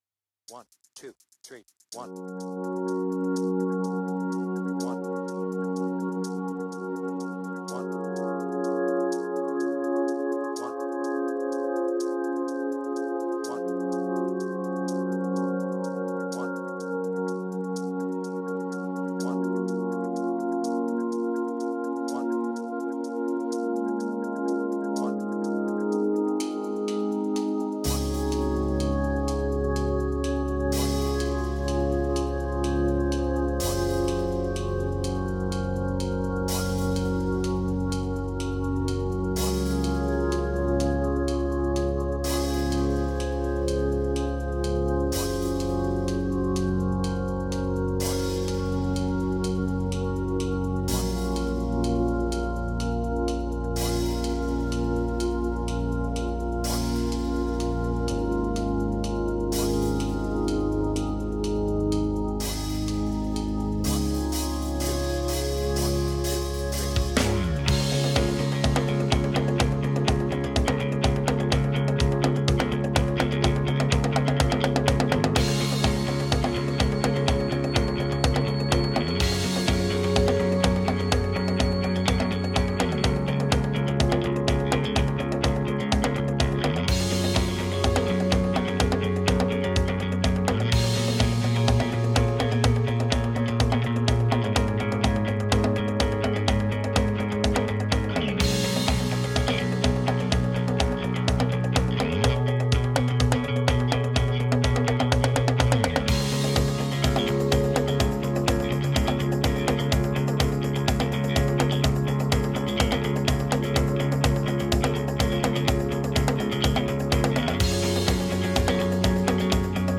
BPM : 125
Tuning : Eb
Without vocals